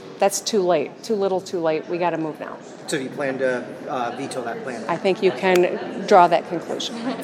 Gov. Gretchen Whitmer was in Portage for the Grand Opening of the new Senior Center on Friday, May 20, and commented on the latest tax cut proposal from the Legislature.